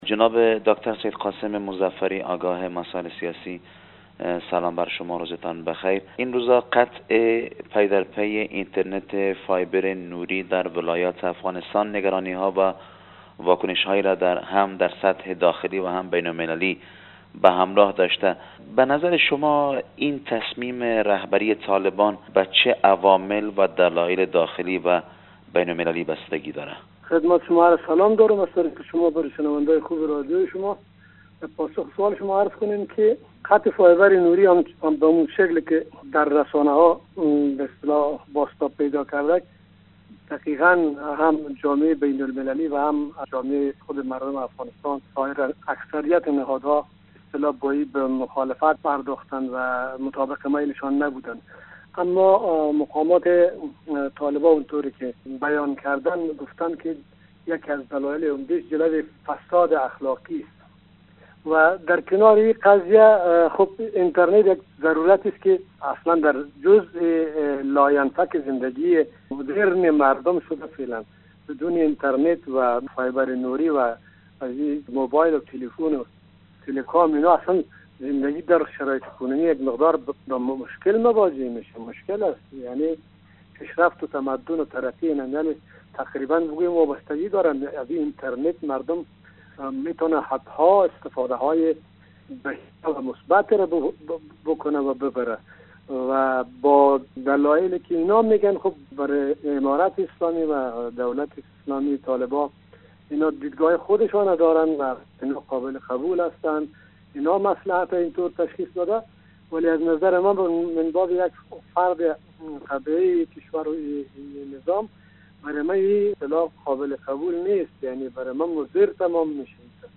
خبر / مصاحبه